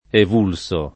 evellere [ ev $ llere ] v.; evello [ ev $ llo ]